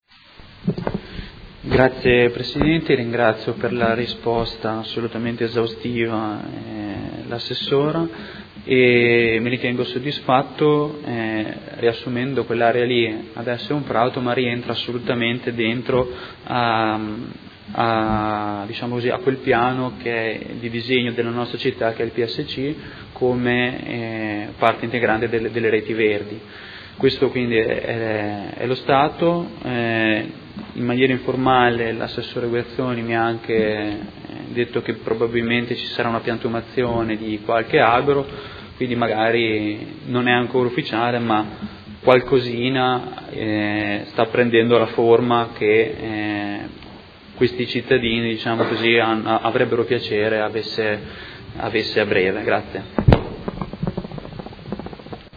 Seduta del 2/2/2017. Interrogazione del Consigliere Lenzini (PD) avente per oggetto: Realizzazione del Parco in Via Borsellino – Via Chinnici in zona elementare 2450, Area 01.